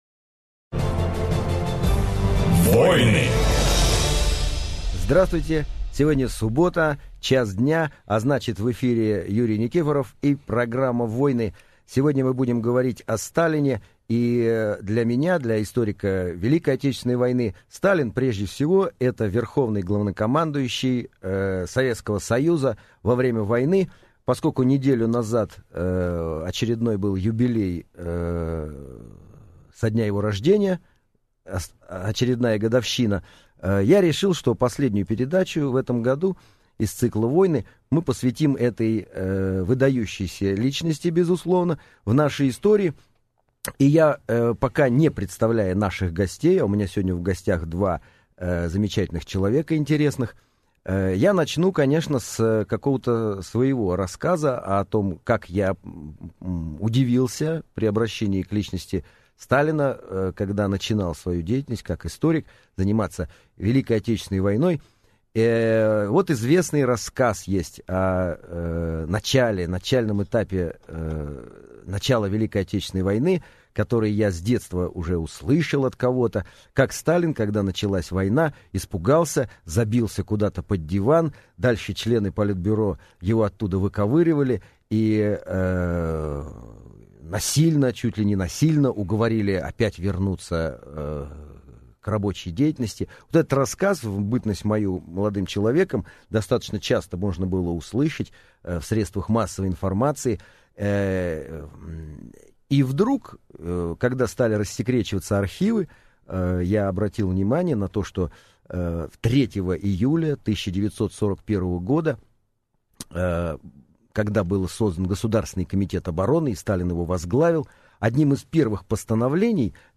Аудиокнига Роль Сталина в войне | Библиотека аудиокниг
Прослушать и бесплатно скачать фрагмент аудиокниги